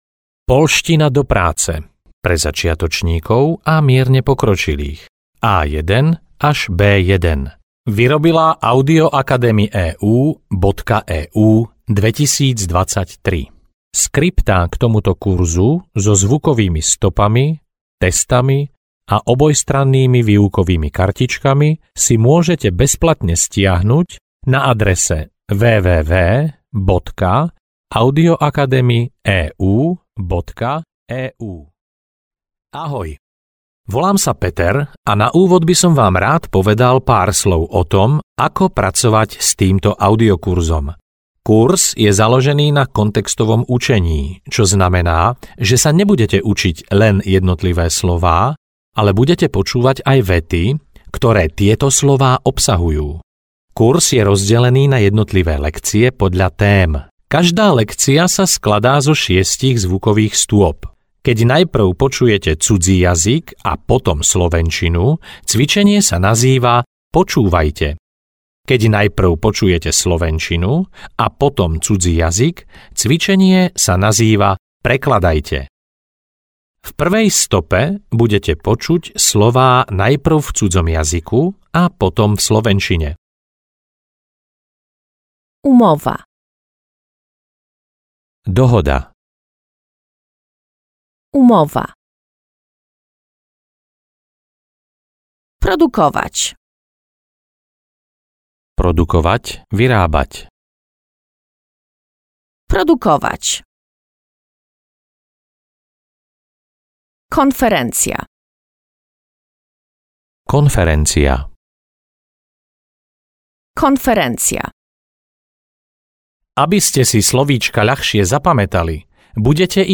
Obchodná poľština A1-B1 audiokniha
Ukázka z knihy